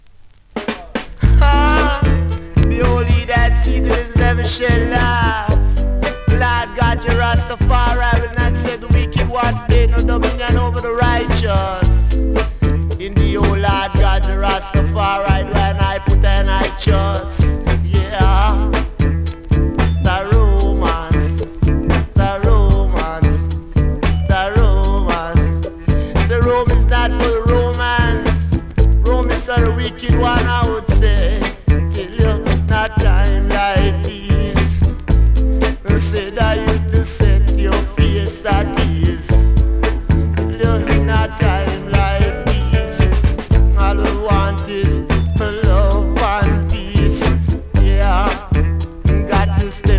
キラーDJ!!
スリキズ、ノイズかなり少なめの